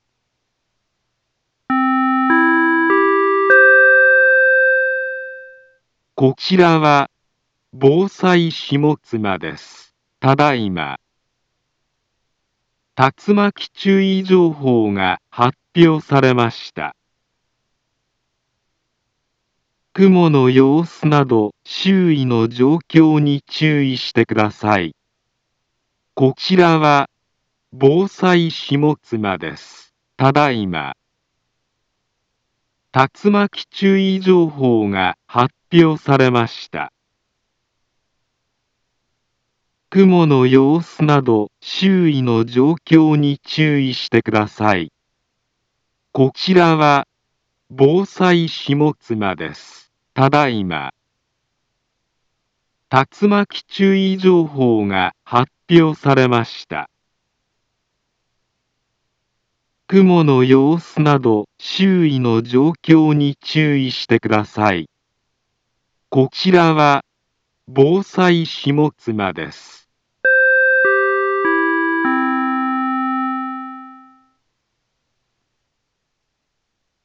Back Home Ｊアラート情報 音声放送 再生 災害情報 カテゴリ：J-ALERT 登録日時：2022-08-02 21:45:16 インフォメーション：茨城県北部、南部は、竜巻などの激しい突風が発生しやすい気象状況になっています。